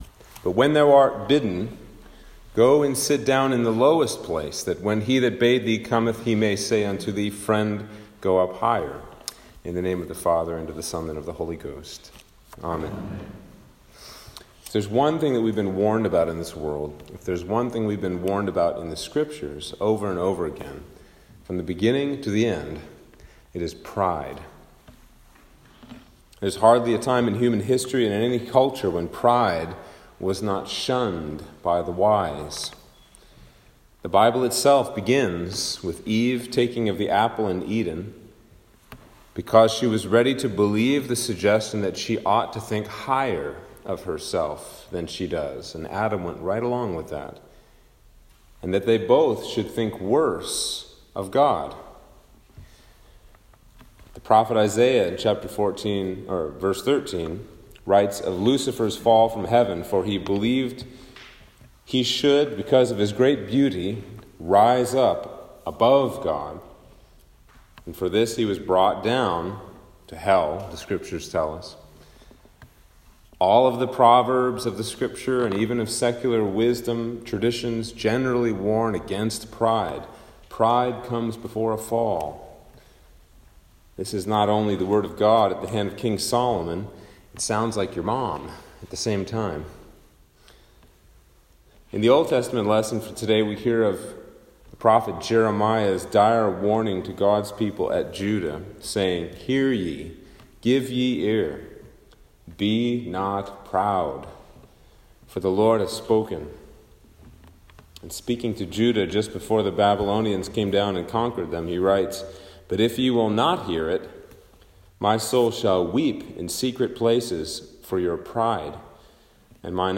Sermon for Trinity 17 - 2021